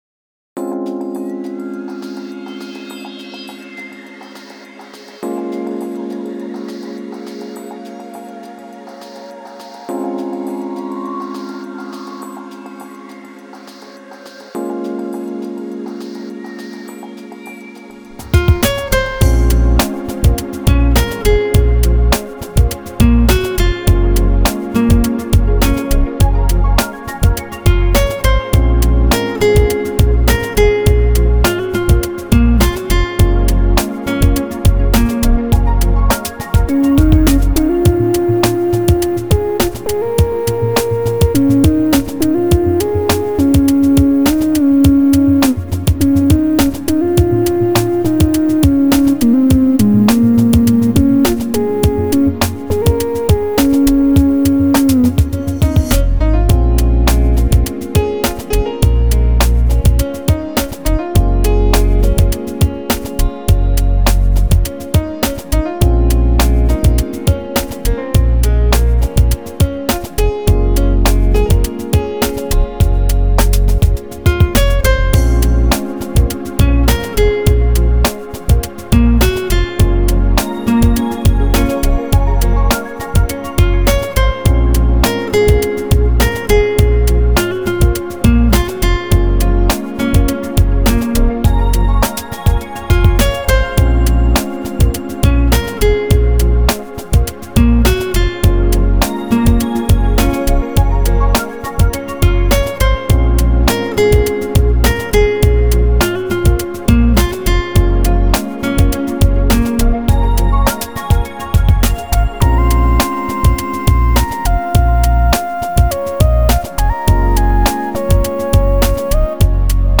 Genre: Smooth Jazz, Chillout, Longe